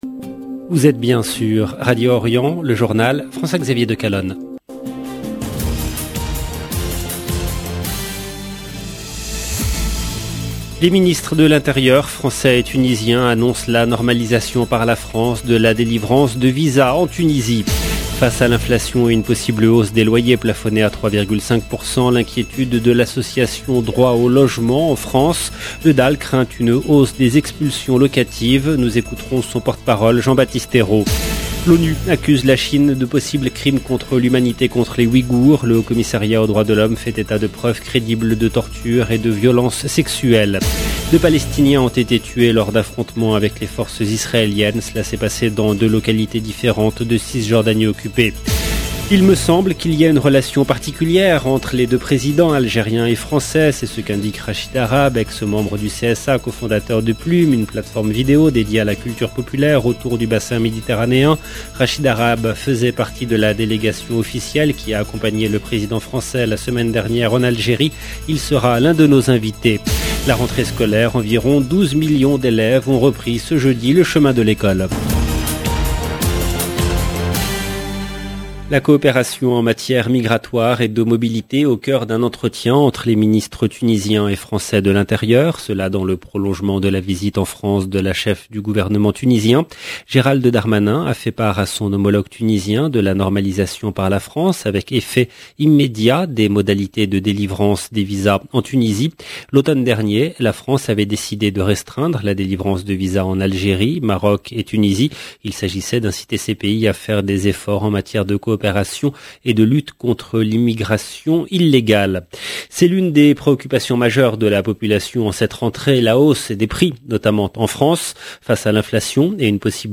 Il sera l’un de nos invités.